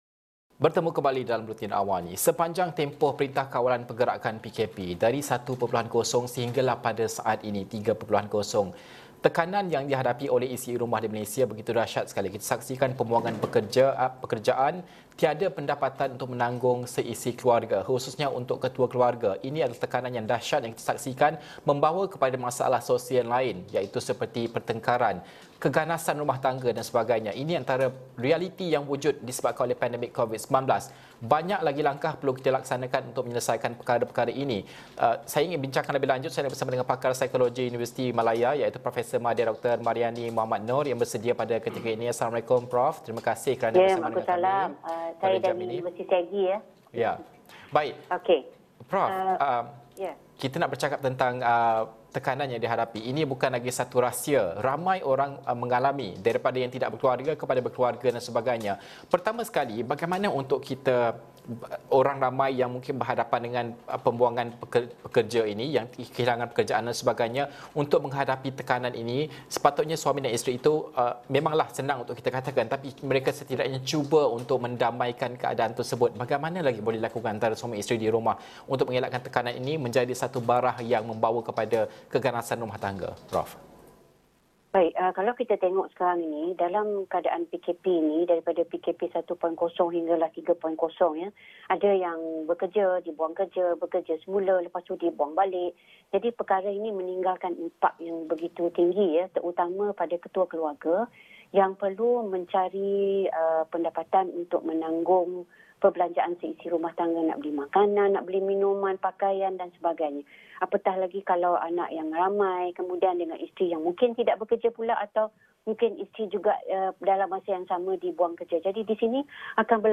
Bersama di talian